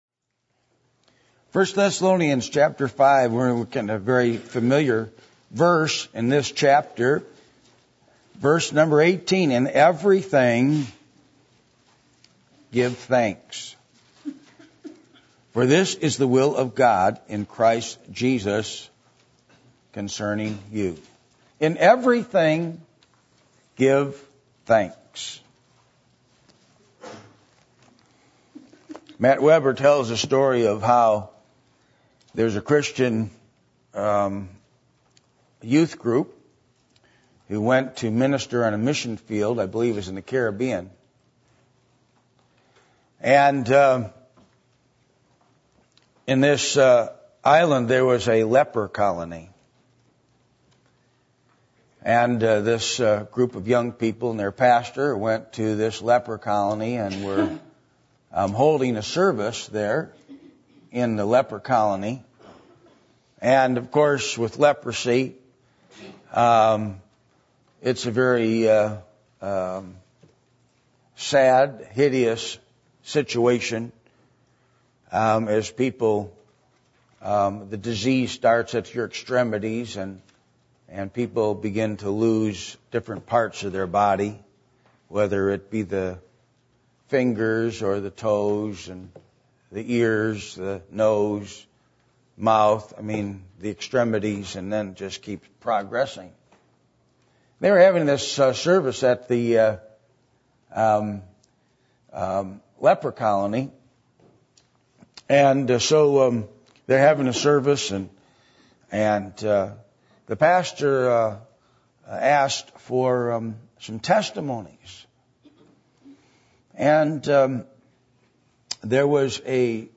1 Corinthians 5:18 Service Type: Sunday Morning %todo_render% « The Great Commission Is For Today!